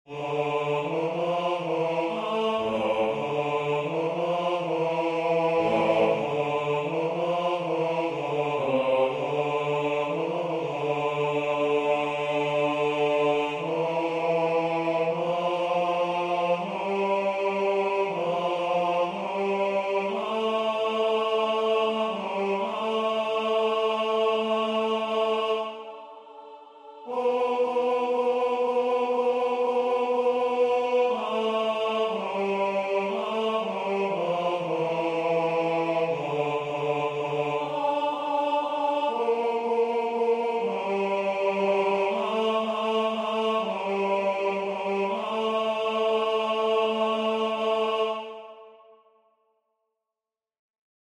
version voix synth.